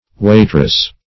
Waitress \Wait"ress\, n.